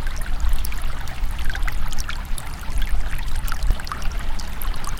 default_flow_water.ogg